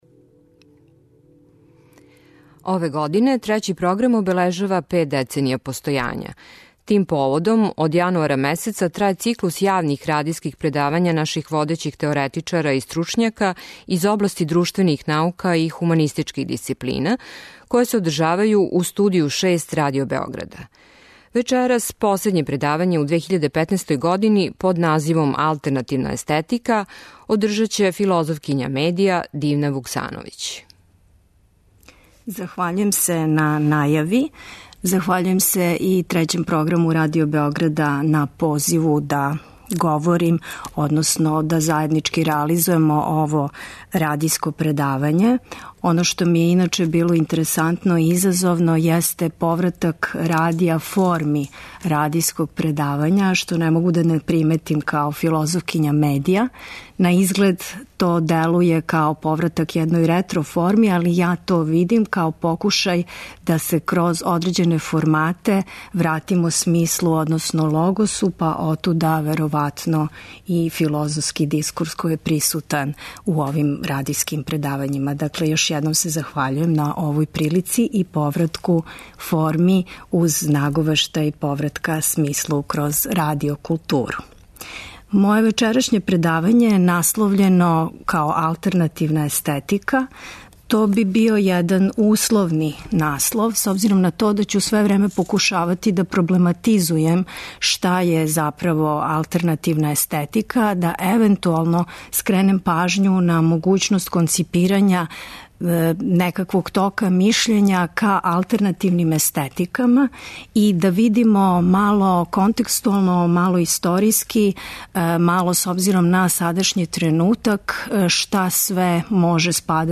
Радијско предавање у Студију 6